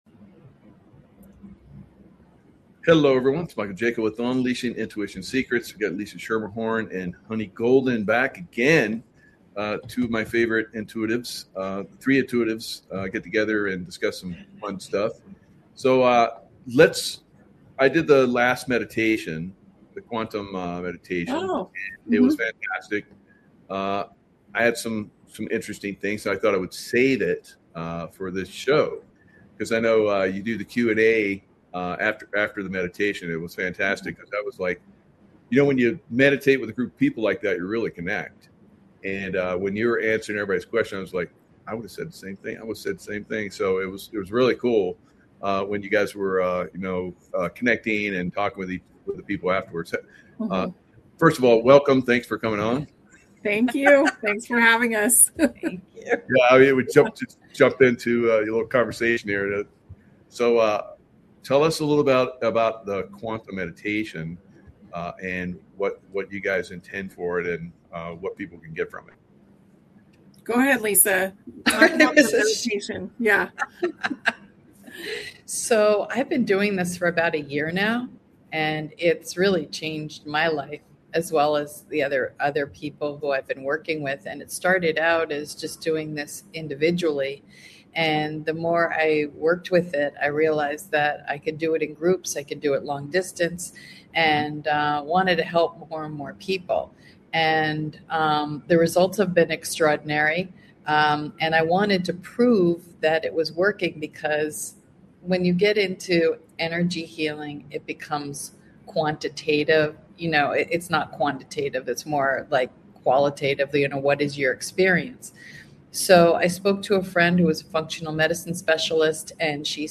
They discuss ancient civilizations like Atlantis and Egypt, and the mysterious Tartaria, known for its advanced technology. The conversation touches on historical events, like the Great Chicago Fire, and speculates about future travel, hinting at trains and portals.